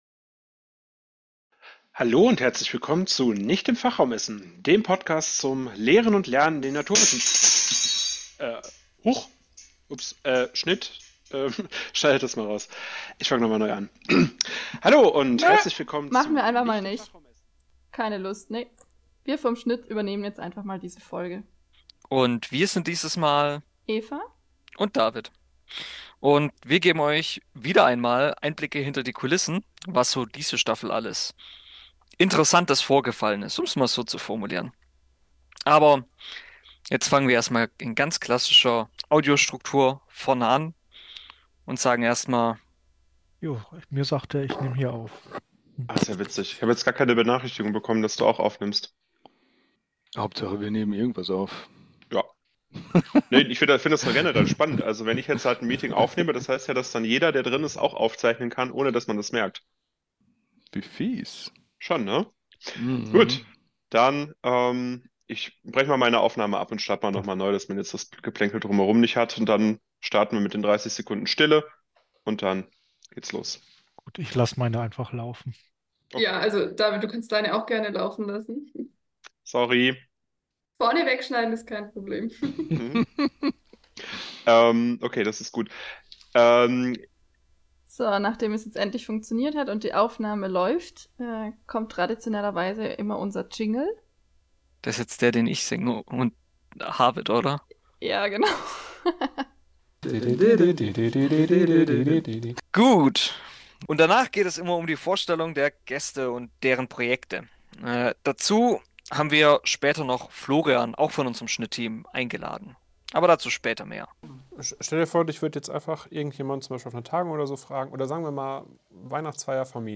Ob spontane Absprachen, kleine Versprecher, unerwartete Lacher oder kuriose Zwischenfälle – hier hört ihr all das, was sonst im Schnitt landet.